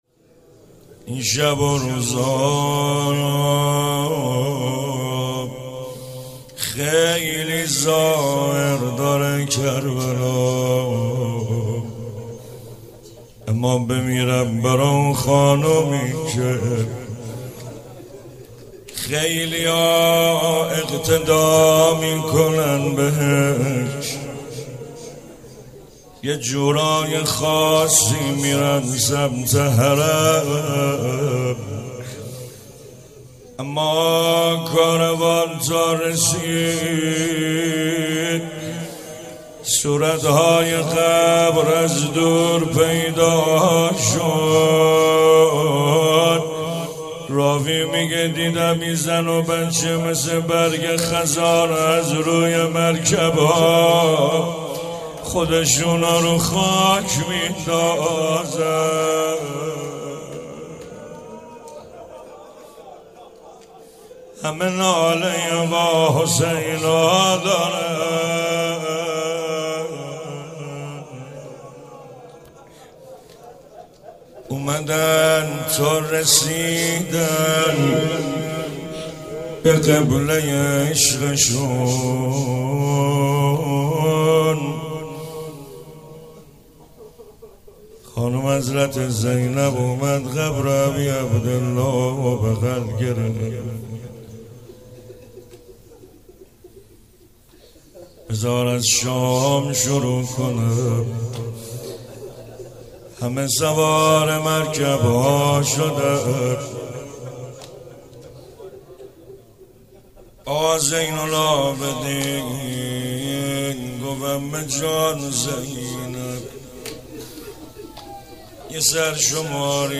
روضه اربعین